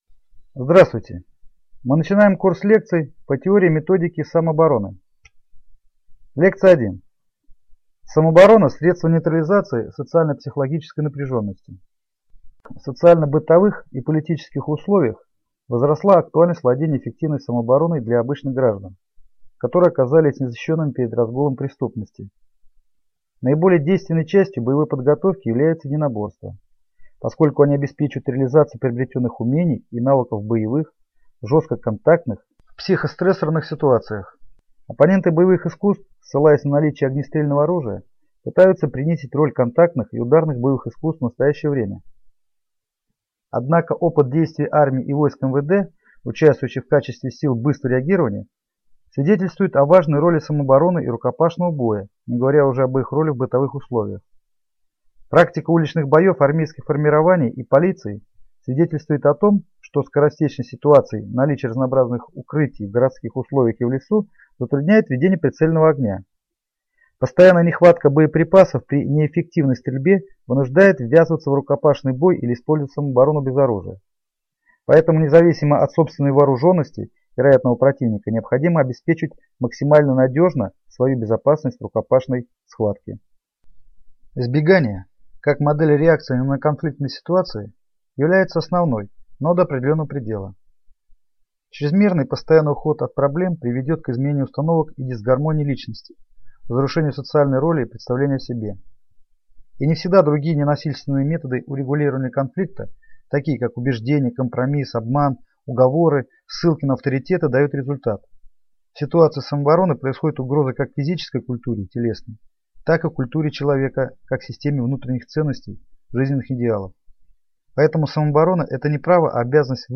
Аудиокнига Лекция 1. Самооборона как средство нейтрализации социально-психологической напряженности | Библиотека аудиокниг